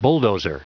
Prononciation du mot bulldozer en anglais (fichier audio)
Prononciation du mot : bulldozer